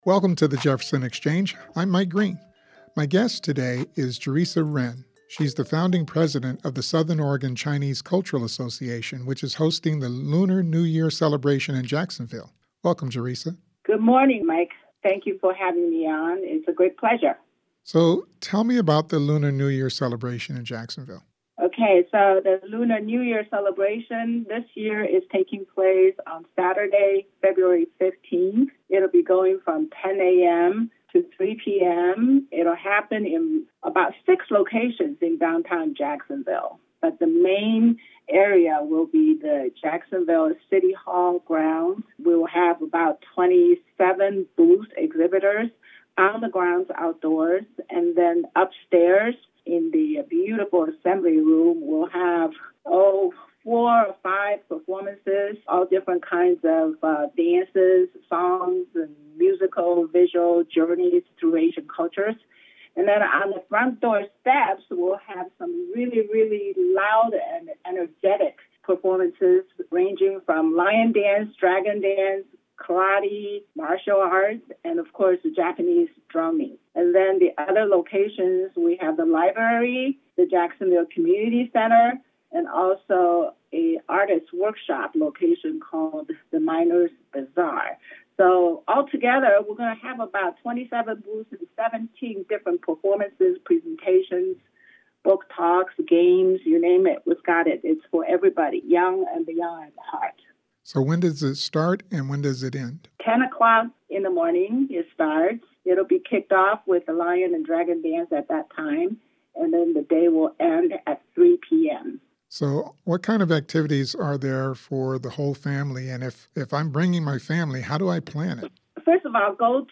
JPR's live interactive program devoted to current events and newsmakers from around the region and beyond.